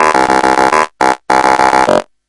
AN1X 酸性循环 " acidline3
描述：一些自制的合成器酸循环，来自雅马哈的AN1X合成器。
Tag: AN1-X 序列 合成器 YAMAHA